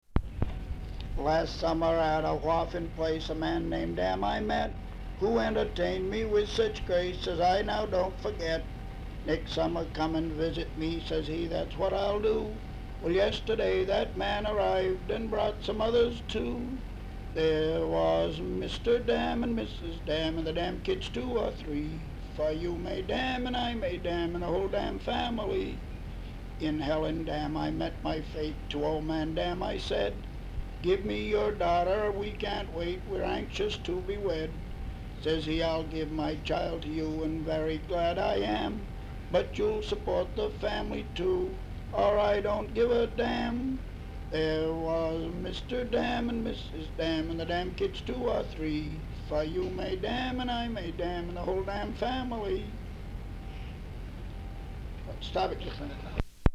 Folk songs, English--Vermont
sound tape reel (analog)
Location Ascutney, Vermont